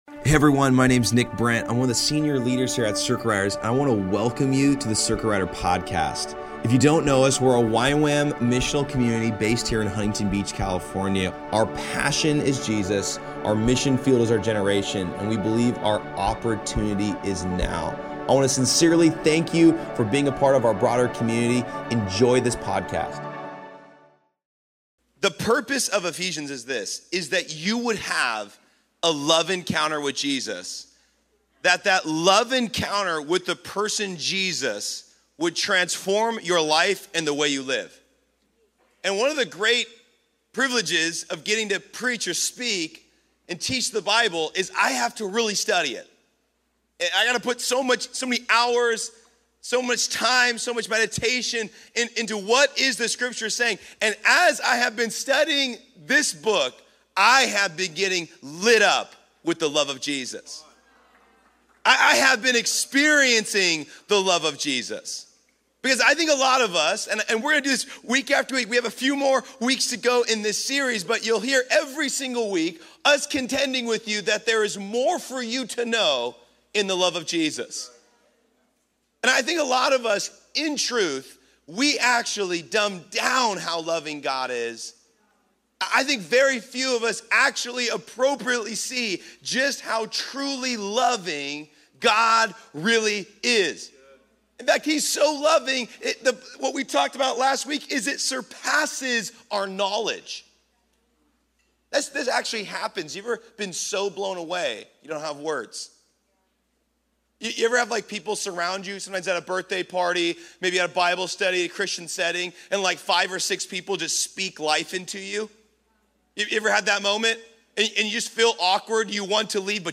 sermon
Details Series Monday Nights Location Huntington Beach Topics Bible